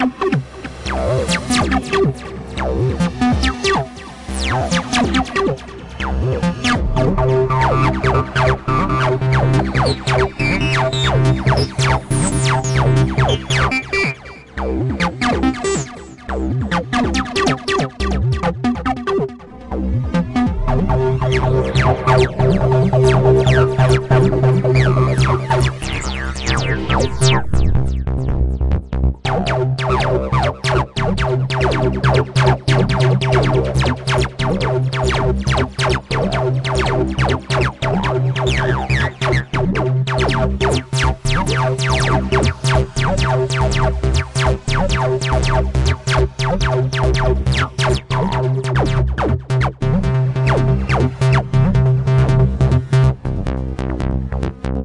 tb303loopb
描述：在ReBirthrb338中创建，并在audacity中对原循环的影子拷贝进行了一些效果编辑.
Tag: 140303 aceed 大胆 BPM 底特律 狂欢 TB 仓库